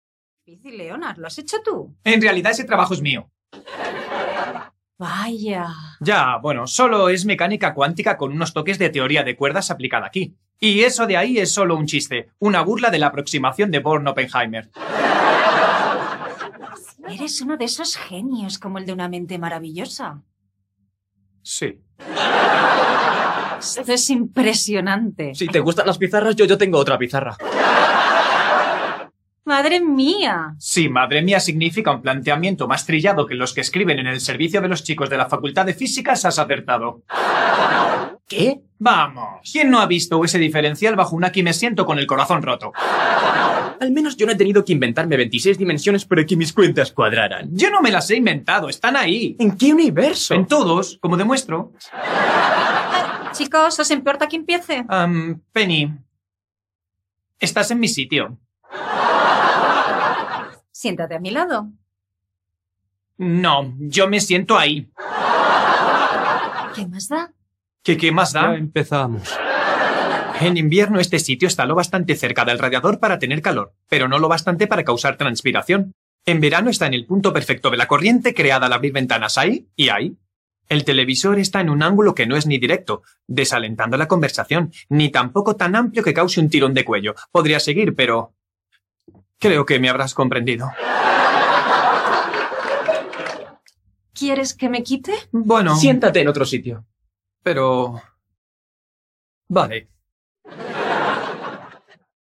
Así suenan algunas de nuestras voces profesionales para vídeos:
ACTOR DOBLAJE
Demo-Doblaje.mp3